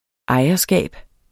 Udtale [ ˈɑjʌˌsgæˀb ]